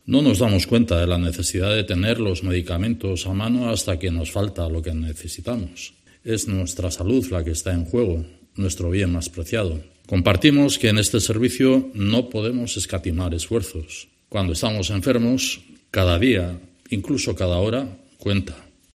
Iñigo Urkullu, lehendakari